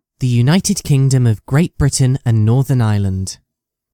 ascultăi/juːˈntɪd ˈkɪŋdəm əv ɡrt ˈbrɪtən ənd ˈnɔːðən ˈələnd/
United_Kingdom_pronunciation.ogg